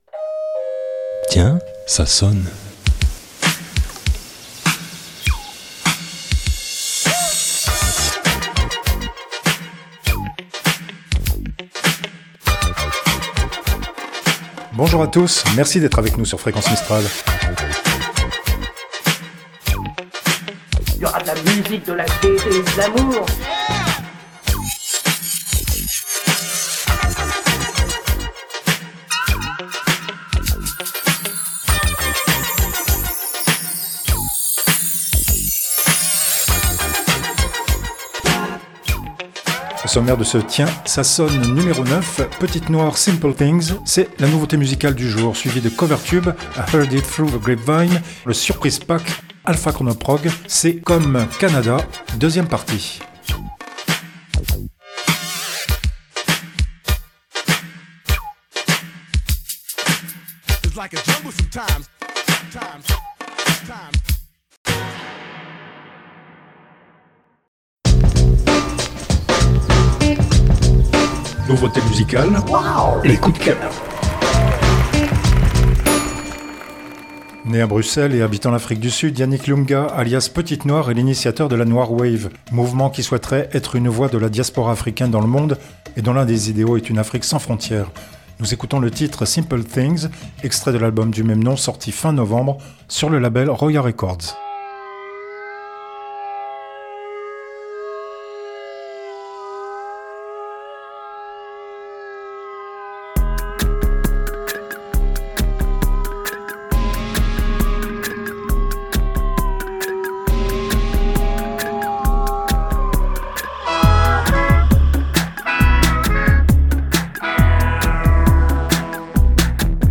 Nouveauté Musicale
Générique & jingles, voix additionnelles